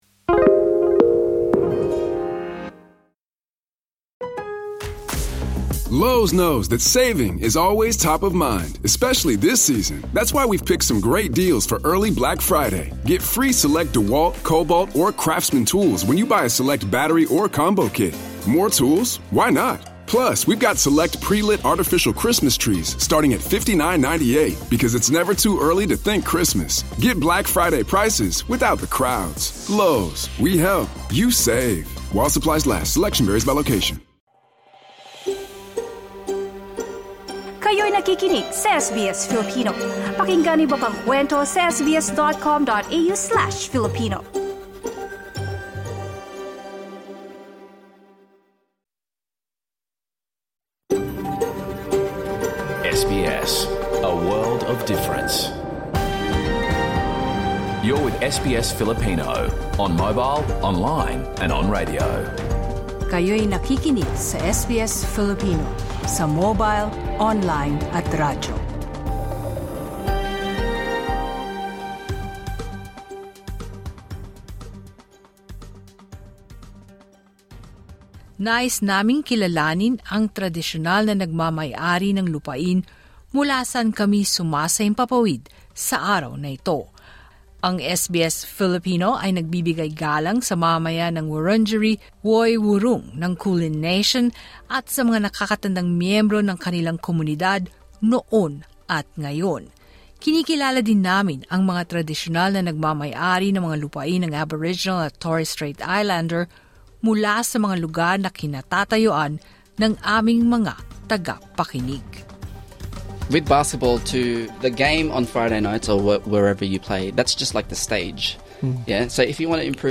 KEY POINTS Filipinos are navigating the aftermath of consecutive typhoons while adjusting to the impact of a weaker peso on daily life. Community groups stress the importance of culturally sensitive social support programs for Filipino-Australian seniors, helping them maintain wellbeing and connection. Interviews with Filipino-Australian basketball mentors reveal strategies for parents to actively support their children in pursuing sports, nurturing both talent and cultural ties.